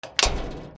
/ K｜フォーリー(開閉) / K05 ｜ドア(扉)
マンション扉 2